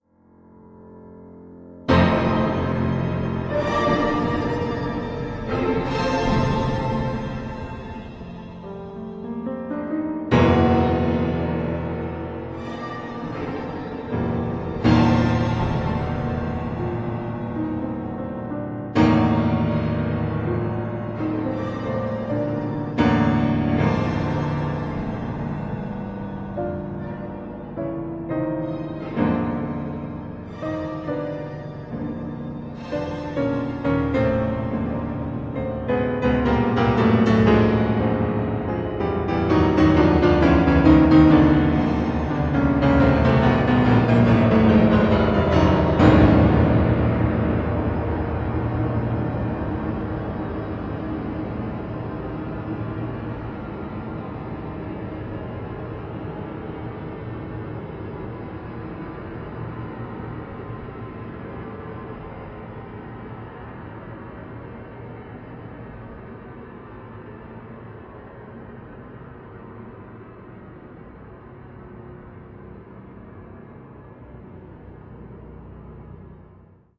for piano
live electronics